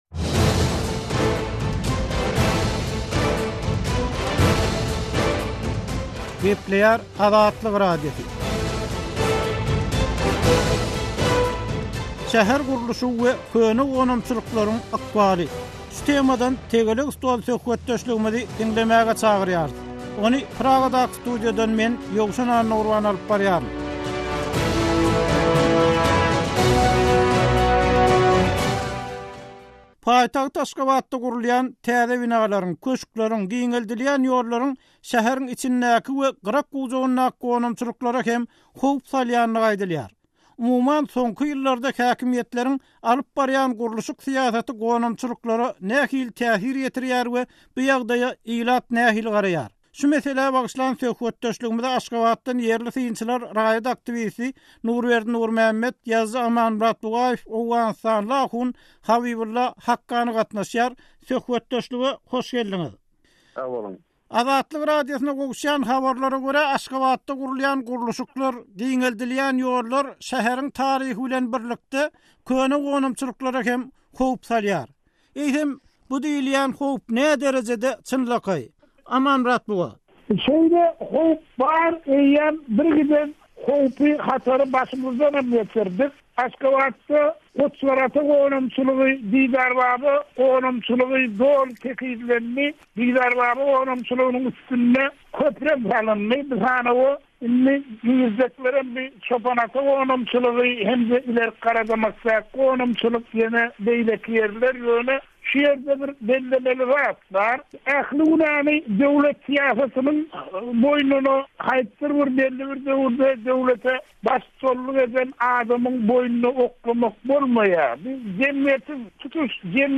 Tegelek stol: Köne gonamçylyklaryň ykbaly